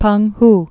(pŭngh)